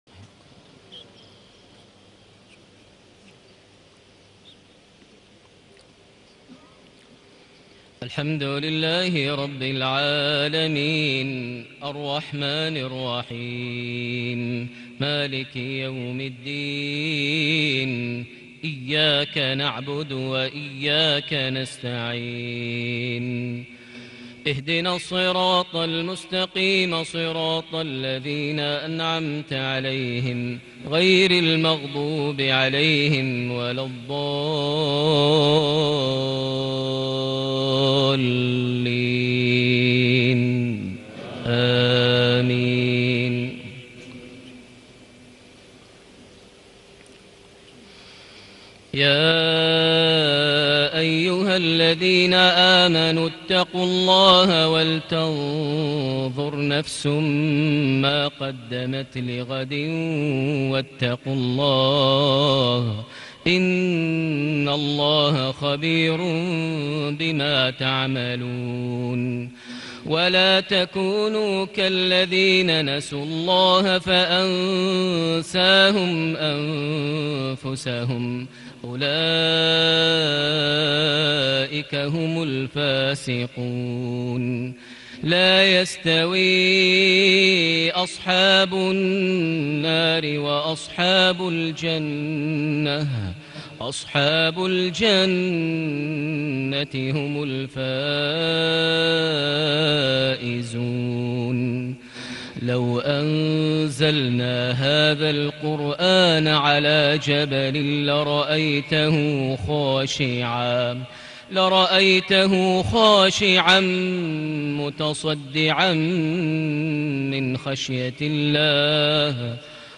صلاة المغرب ١٠ ربيع الآخر ١٤٣٨هـ سورة الحشر ١٨-٢٤ > 1438 هـ > الفروض - تلاوات ماهر المعيقلي